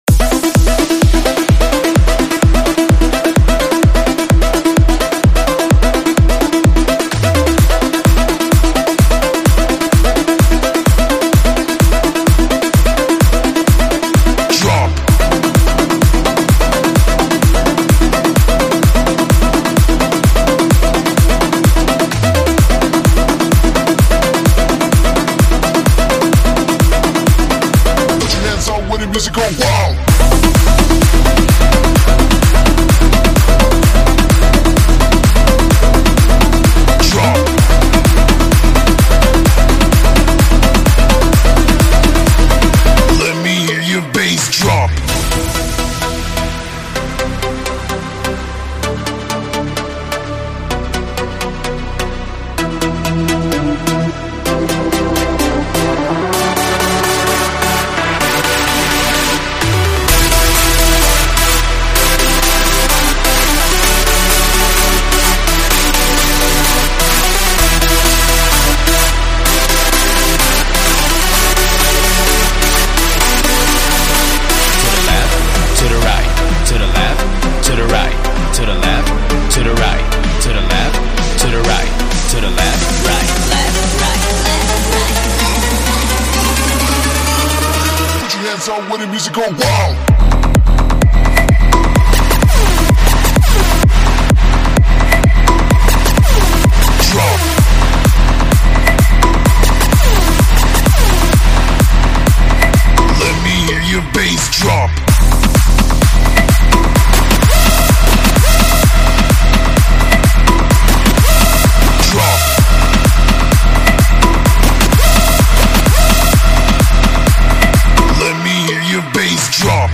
试听文件为低音质，下载后为无水印高音质文件 M币 8 超级会员 免费 购买下载 您当前未登录！